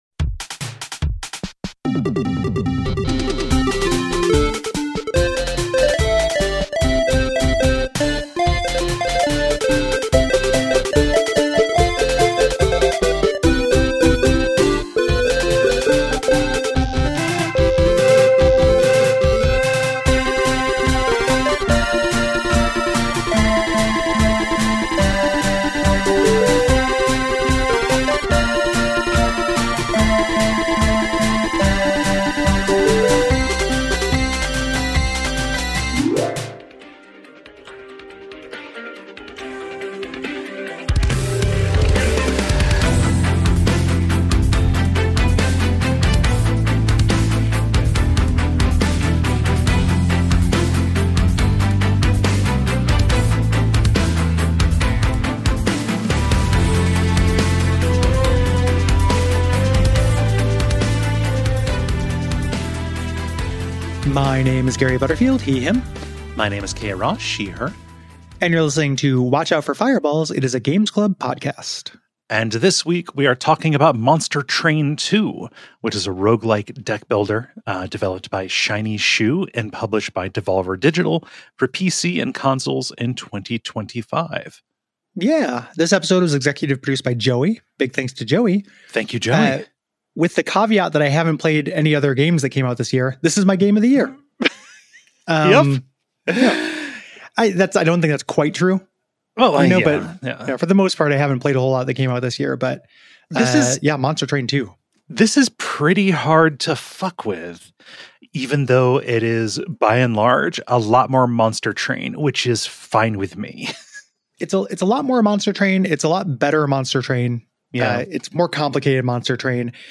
1 IGN’s Top 100 Nintendo Games LIVE REACTION - Kinda Funny Gamescast 2:03:01